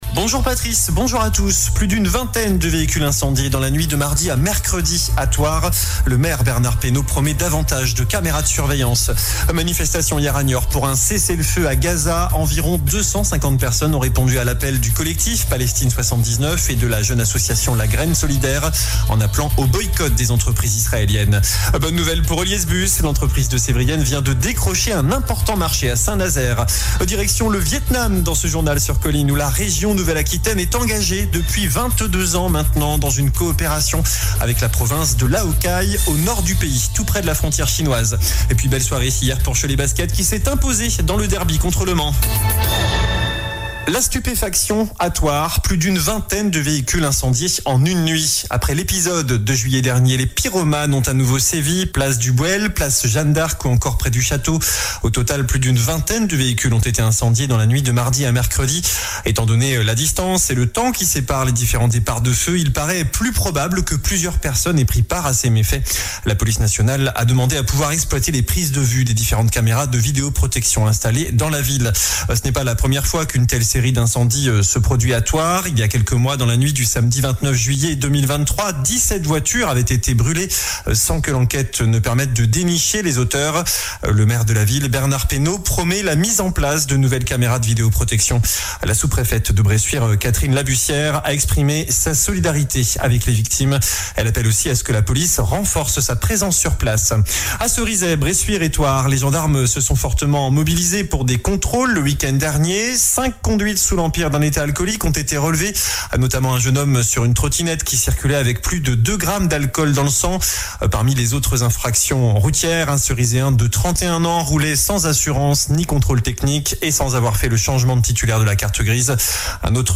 JOURNAL DU JEUDI 28 DECEMBRE ( MIDI )